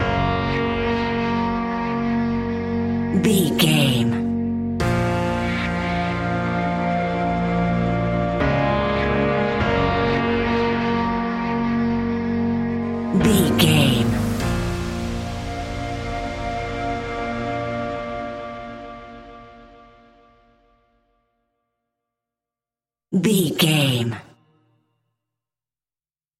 In-crescendo
Thriller
Aeolian/Minor
ominous
suspense
eerie
horror music
Horror Pads
horror piano
Horror Synths